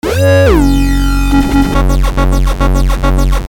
硬朗的Dubstep低音循环
描述：在佛罗里达州的9号工作室制作
Tag: 140 bpm Dubstep Loops Bass Loops 591.57 KB wav Key : Unknown